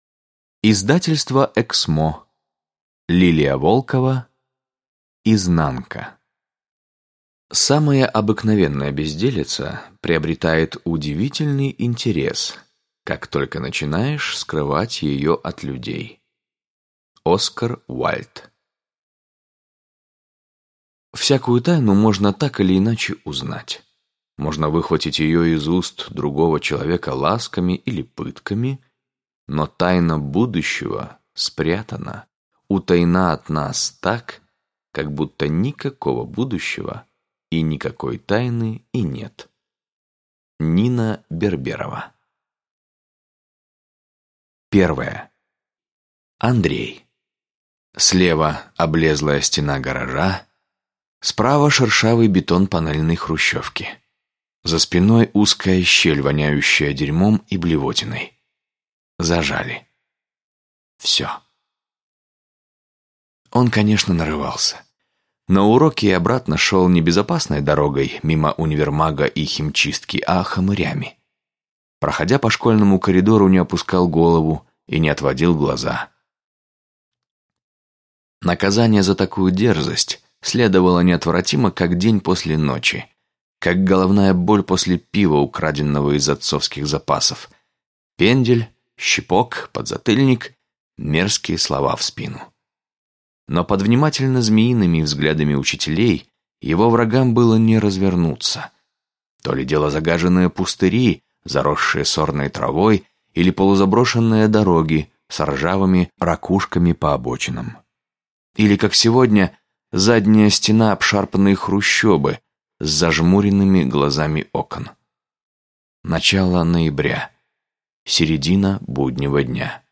Аудиокнига Изнанка | Библиотека аудиокниг